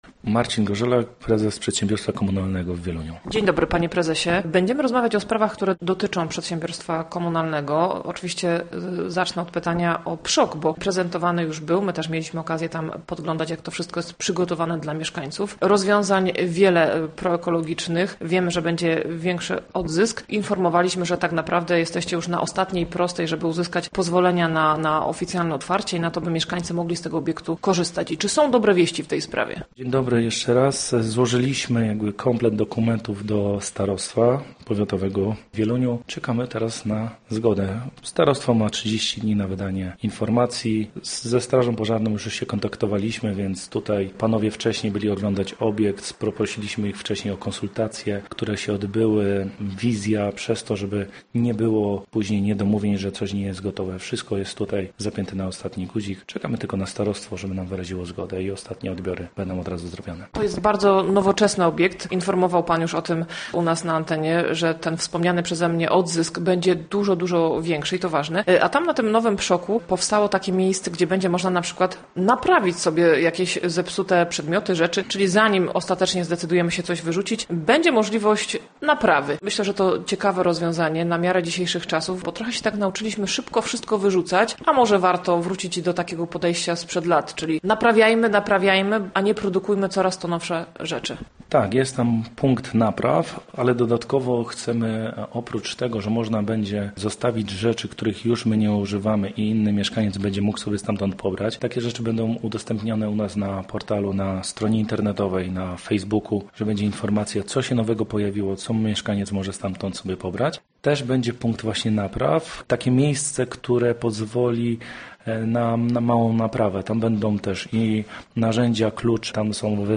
Na koniec o akcji charytatywnej związanej z przekazywaniem używanych zabawek. Zapraszamy do rozmowy!